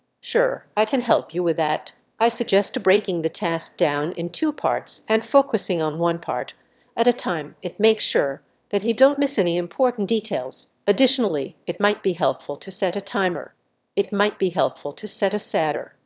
stress1_Munching_1.wav